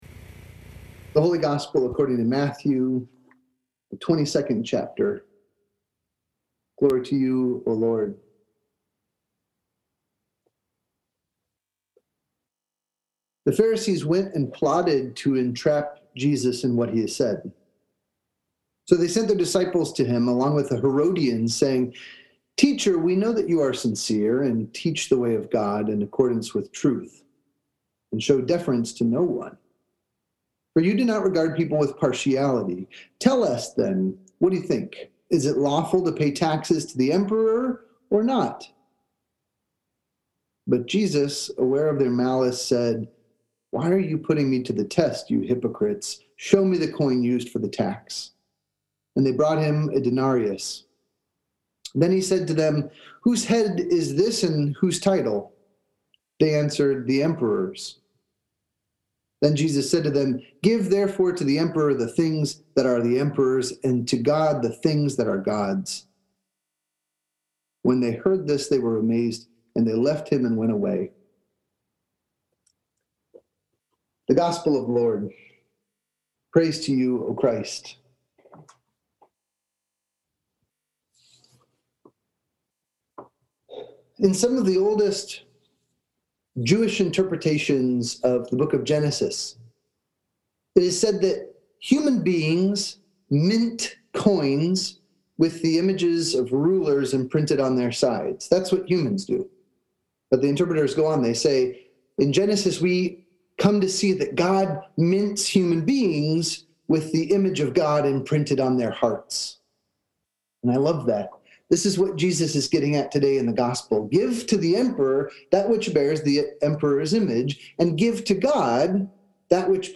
Sermons | Shepherd of the Valley Lutheran Church